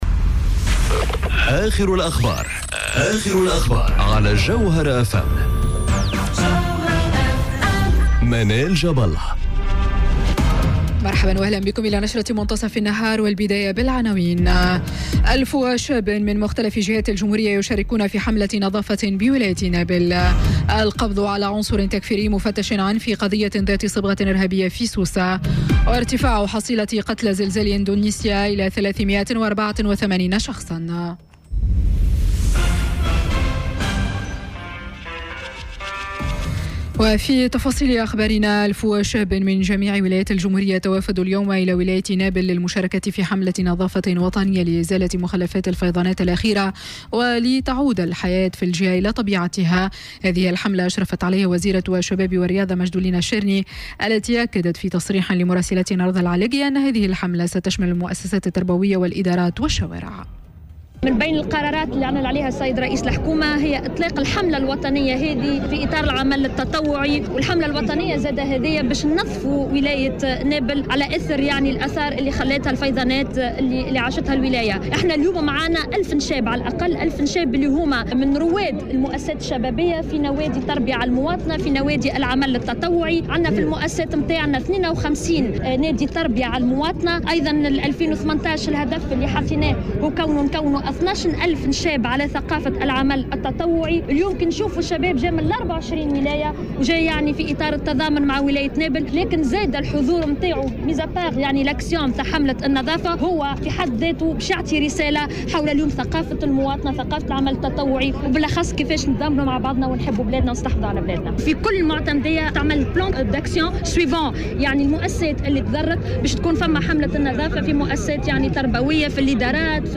نشرة أخبار منتصف النهار ليوم السبت 29 سبتمبر 2018